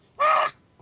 Screech